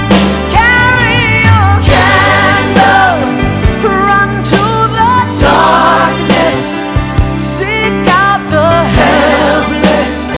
The contemporary Christian song